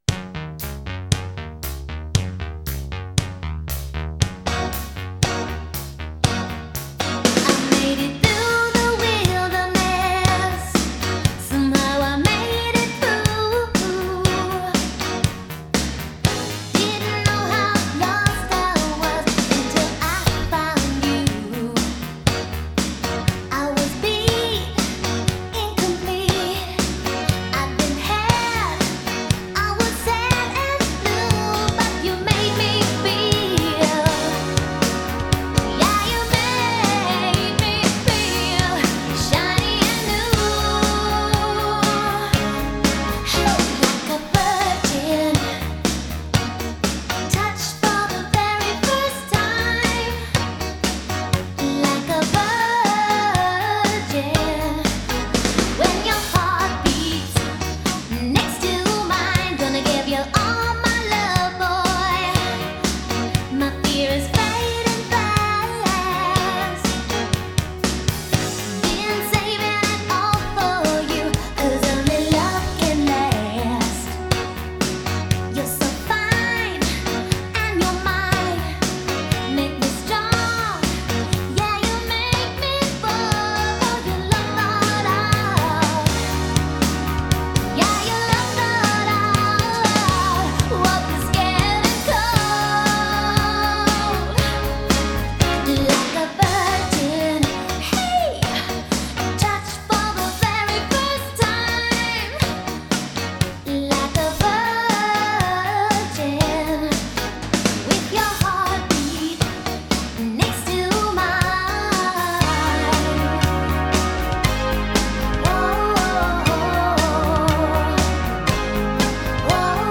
Pop, Dance-Pop